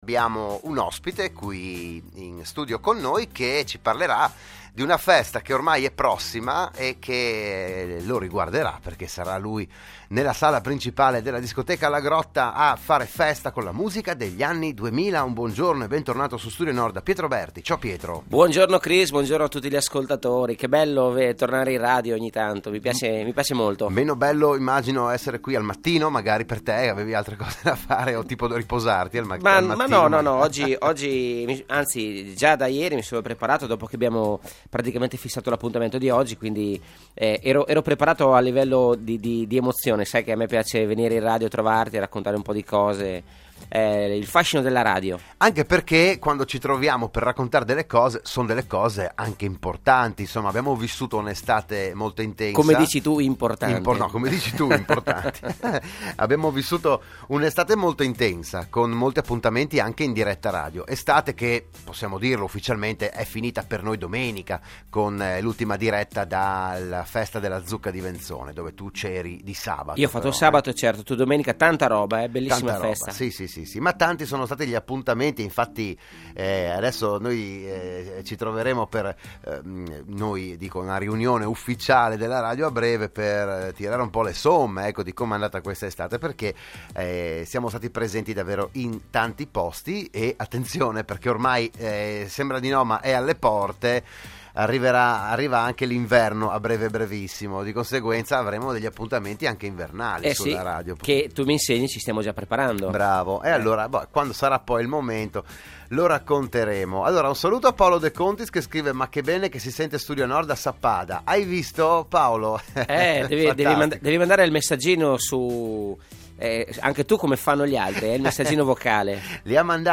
Il PODCAST dell'intervento a Radio Studio Nord